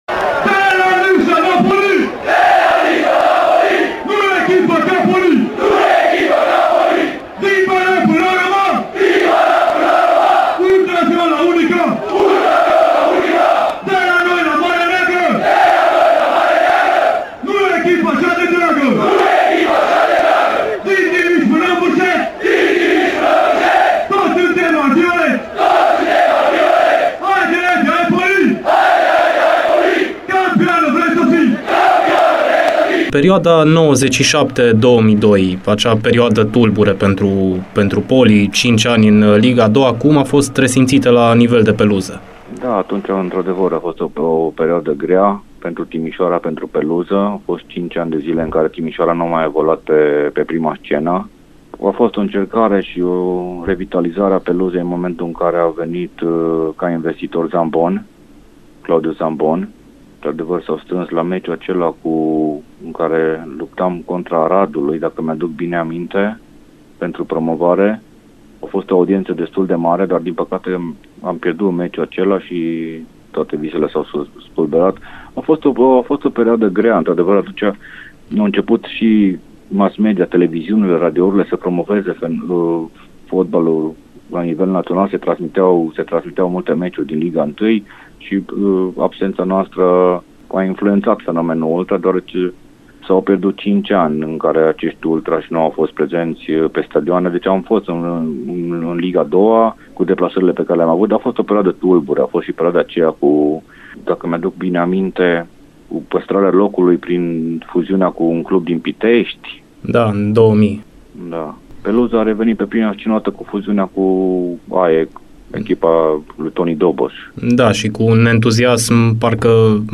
Despre toate aceste momente, în partea a doua a dialogului